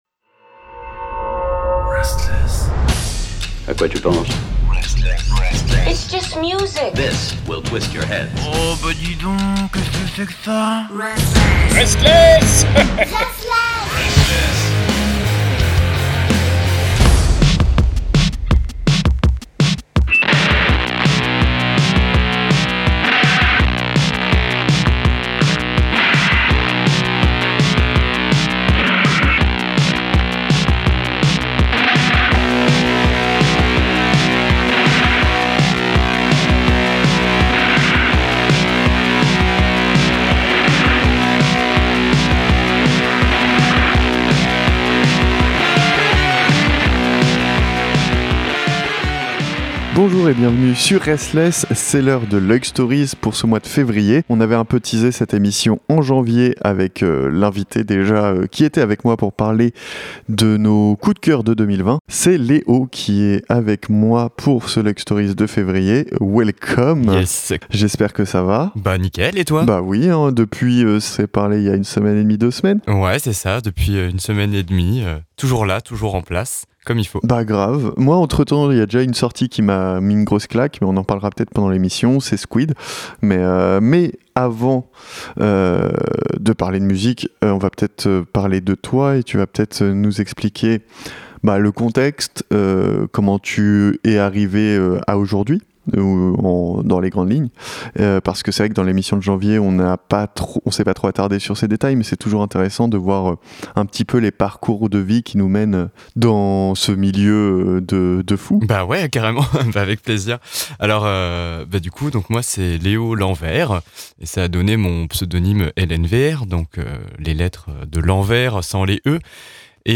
Luik Stories c’est l’émission belge qui explique le monde de la musique avec des artistes en invités. C’est tous les 2ème et 4ème mercredis du mois à 20h sur RSTLSS Radio.